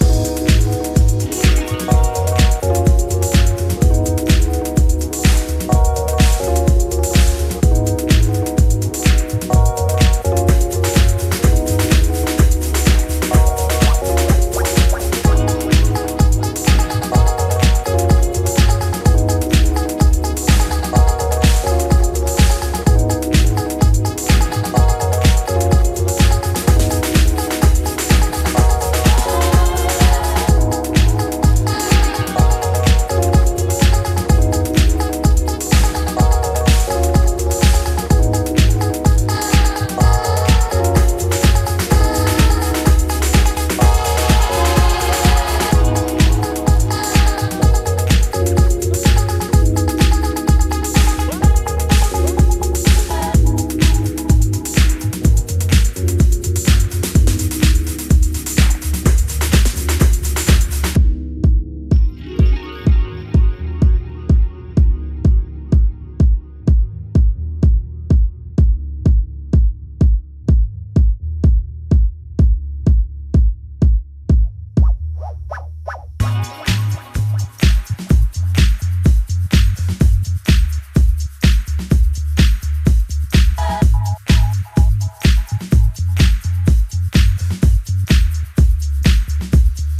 多彩なグルーヴやオーガニックでタッチでオーセンティックなディープ・ハウスの魅力を醸し、モダンなセンスで洗練された意欲作。